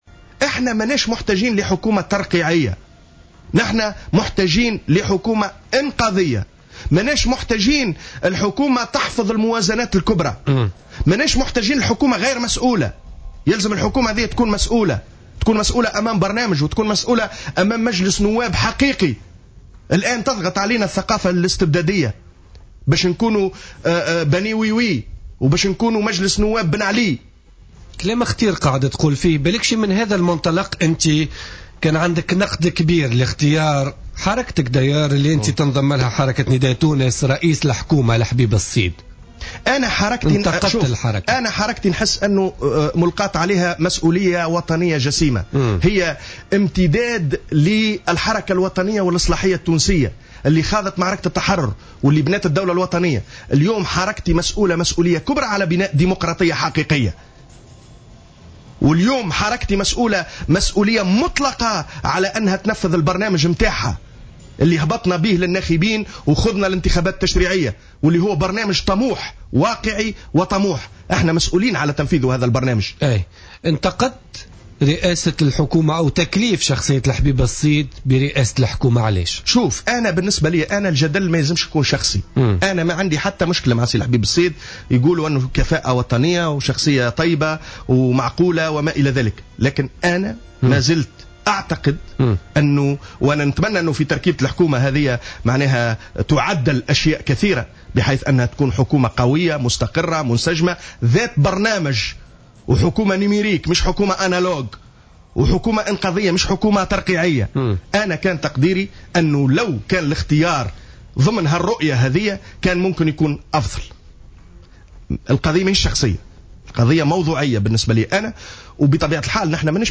Le dirigeant de Nidaa Tounes et député à l'ARP, Khaled Chouket a estimé lundi lors de son passage sur le plateau de Jawhara Fm, le futur gouvernement doit être un gouvernement fort qui porte un programme d’avenir de sorte qu’il soit un gouvernement « numérique » et non un gouvernement « analogique ».